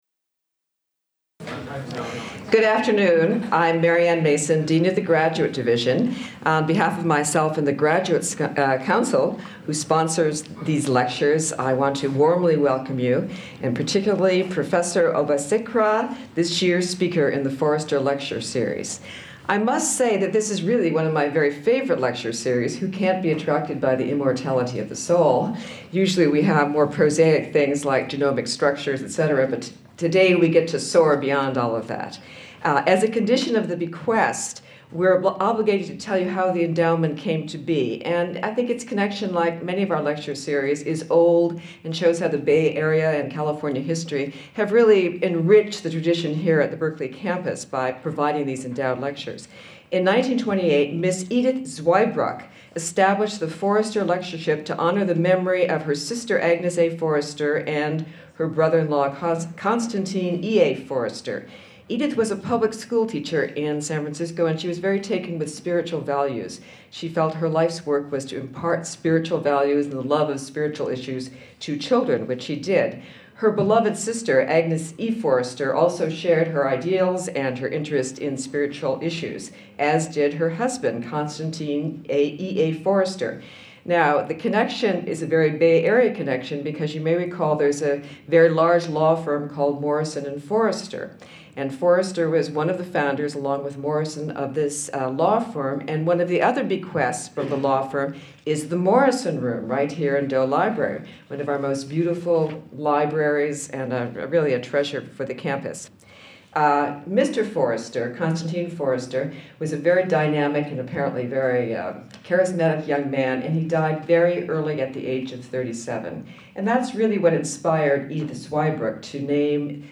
Foerster Lectures on the Immortality of the Soul